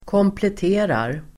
Uttal: [kåmplet'e:rar]